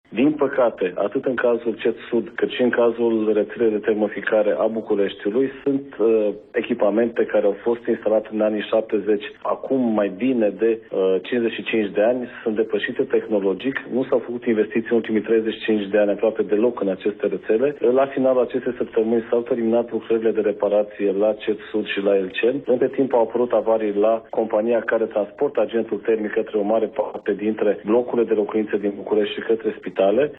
Explicațiile ministrului la postul public de televiziune.
Ministrul Energiei, Bogdan Ivan: „Nu s-au făcut investiții în ultimii 35 de ani aproape deloc la aceste rețele de termoficare”